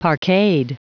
Prononciation audio / Fichier audio de PARQUETED en anglais
Prononciation du mot : parqueted